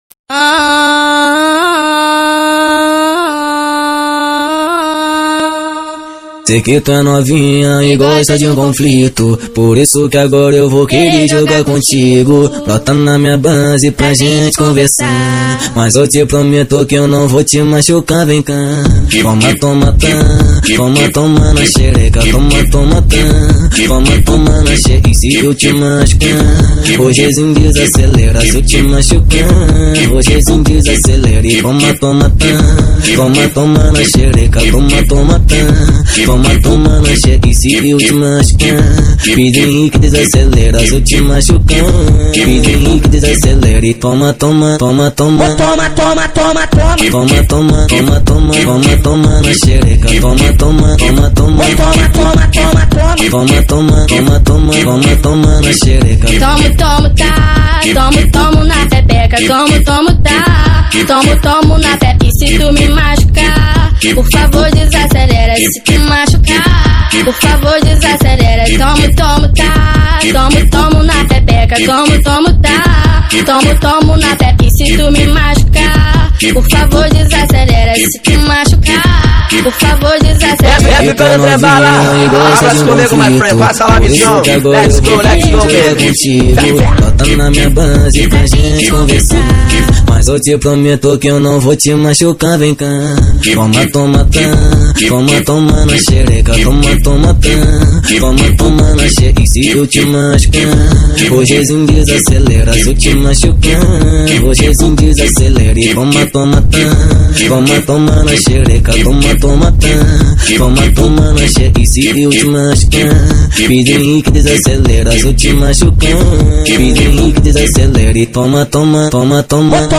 2024-10-30 16:19:16 Gênero: Funk Views